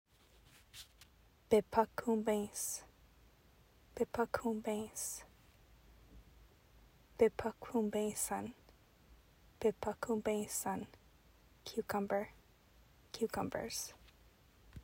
Anishinaabemowin pronunciation: "bi-puh-koom-bayns (un)"